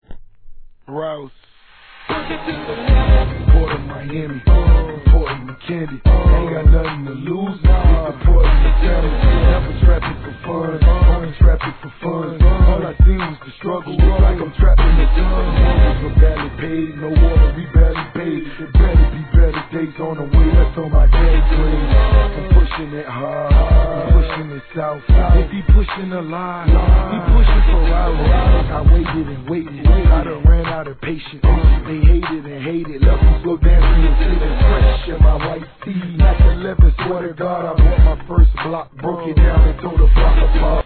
HIP HOP/R&B
今回は'80sのロックをサンプリング!!
声ねたもバッチリはまって、これも強烈な仕上がりです♪